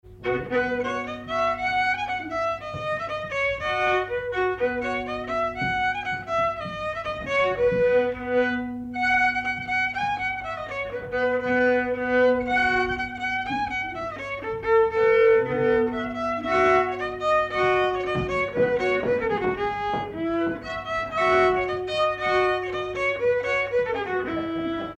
Villard-sur-Doron
circonstance : bal, dancerie
Pièce musicale inédite